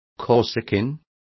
Complete with pronunciation of the translation of corsican.